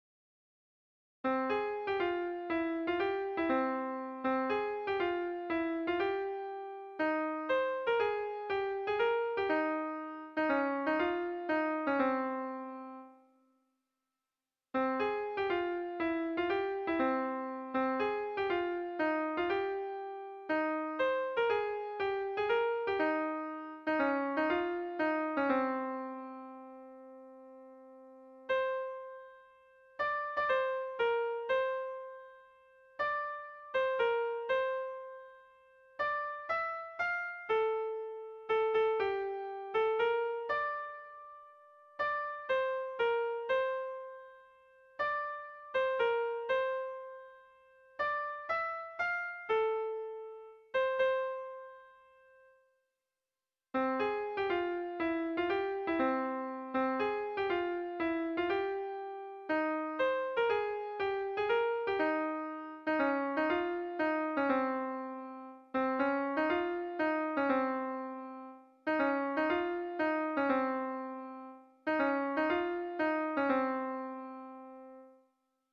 sopranes-mp3 10 février 2022